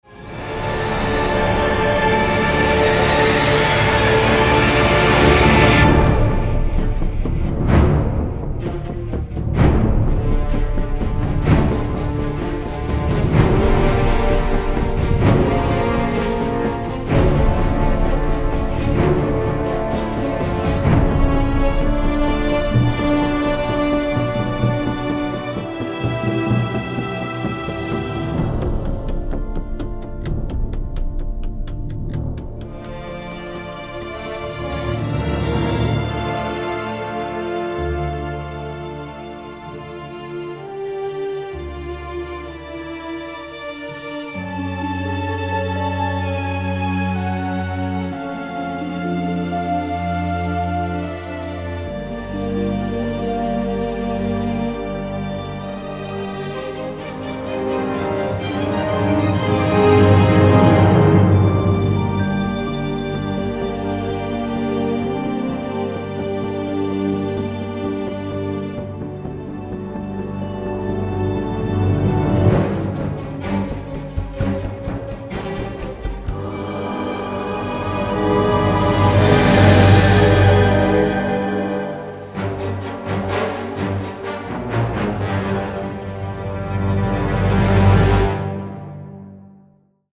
original motion picture score to the all-new
wildly fun and exhilarating music score